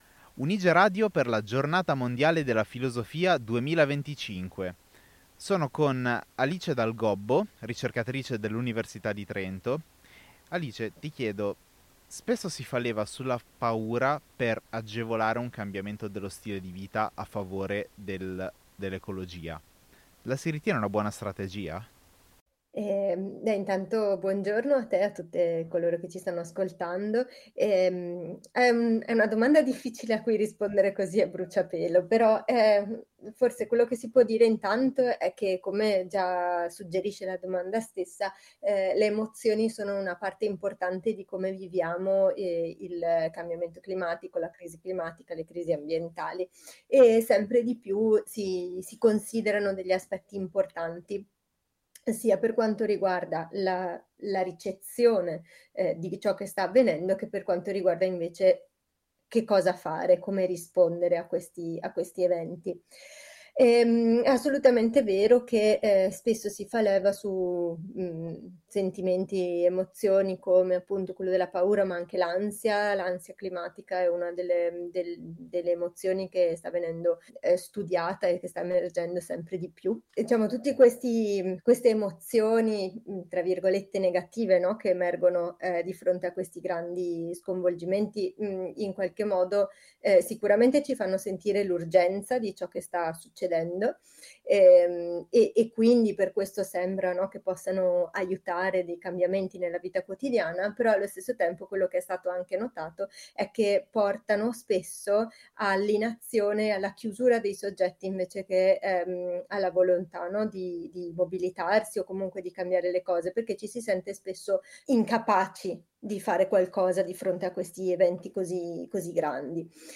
Un dialogo filosofico sull’urgenza del presente e sulla desiderabilità dei futuri ecologici.